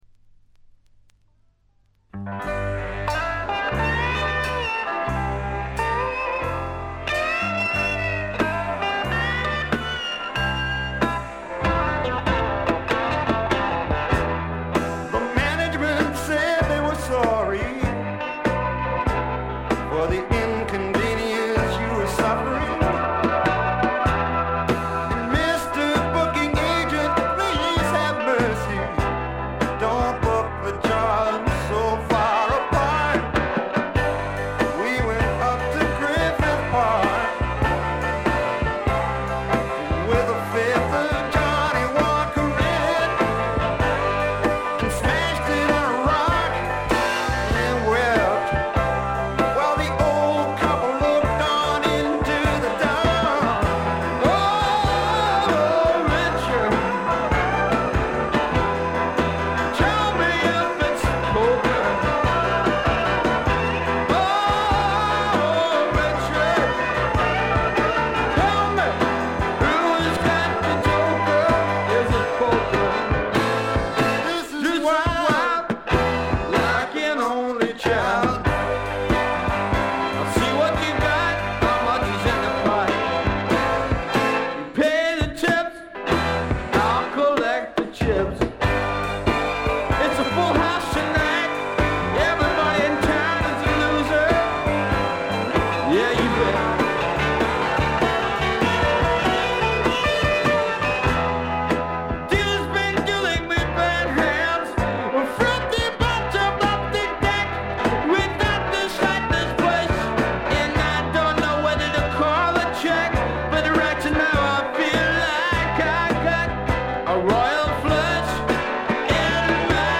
部分試聴ですが、軽微なチリプチ程度。
試聴曲は現品からの取り込み音源です。